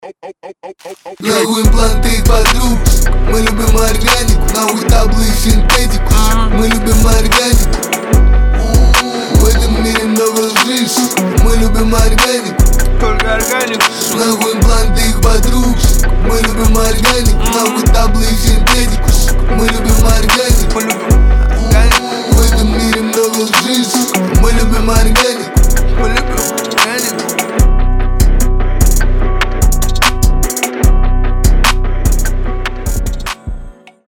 рэп